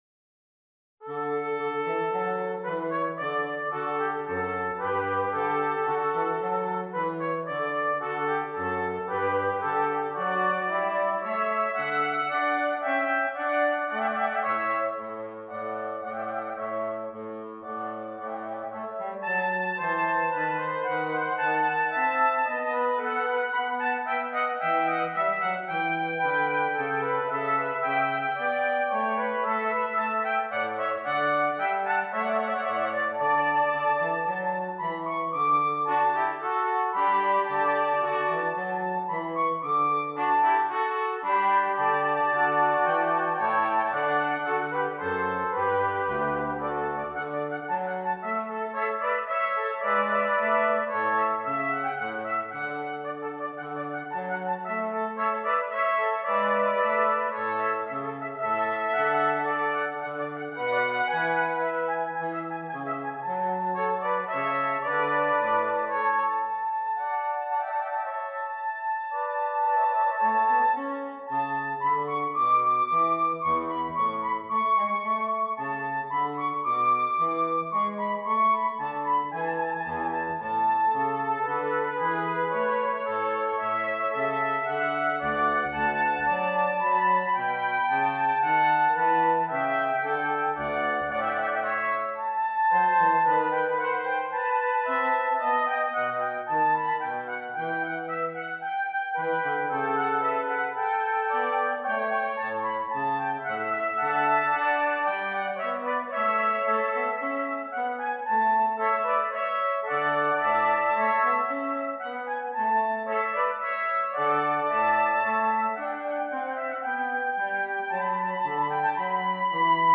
3 Trumpets and Bass Clef Instrument